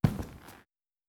grunk / assets / sfx / footsteps / plastic / plastic2.wav
plastic2.wav